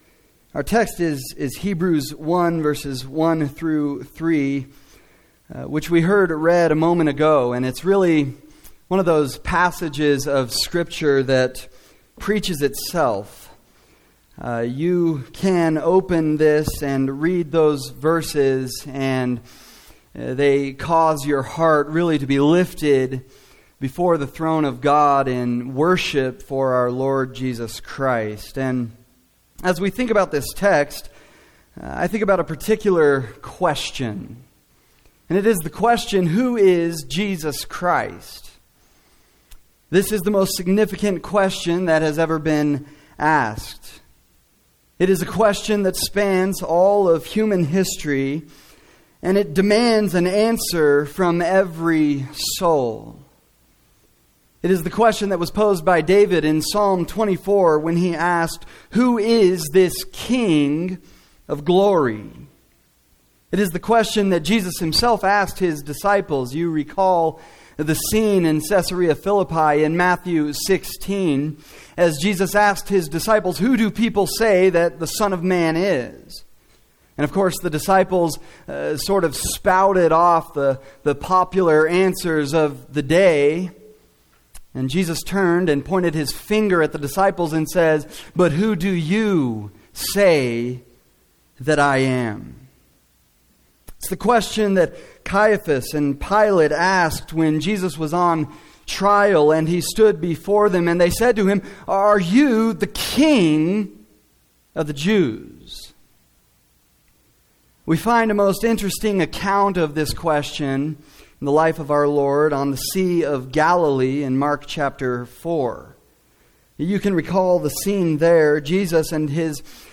The message from guest speaker